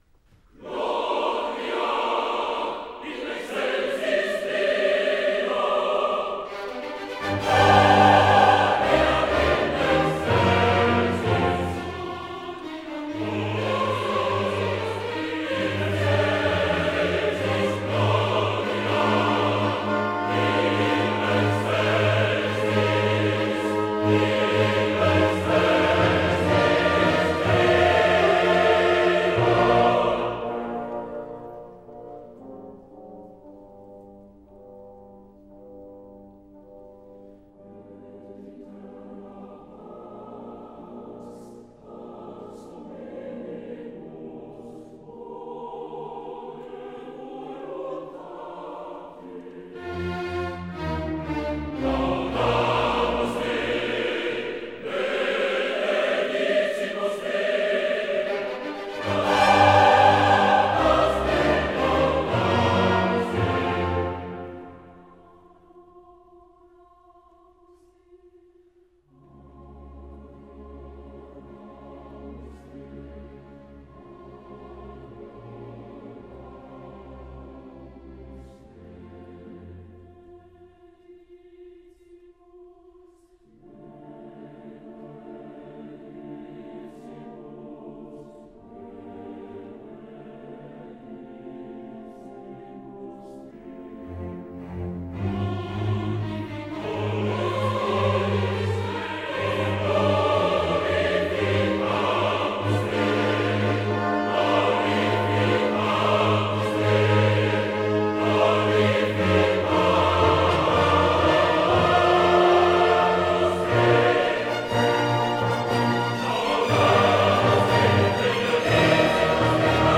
Claudia Abbado and the Vienna Philharmonic Orchestra